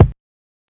KICKPUNCH4.wav